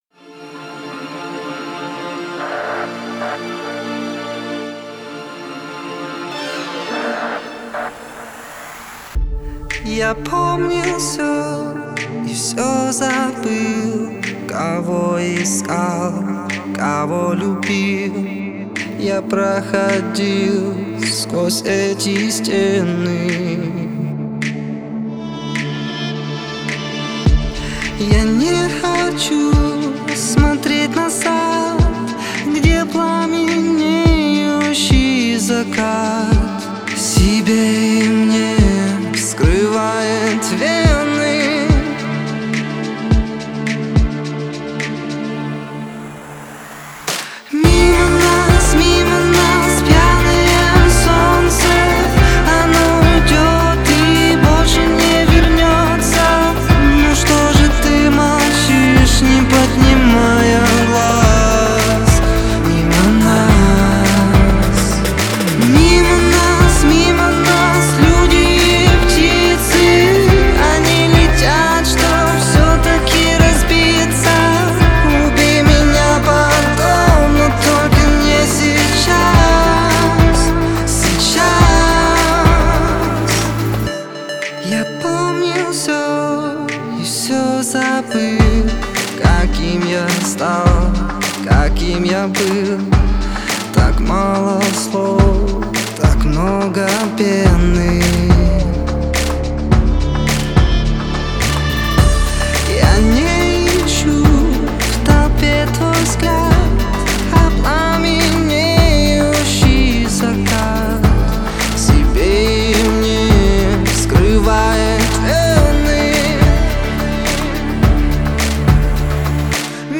медленные песни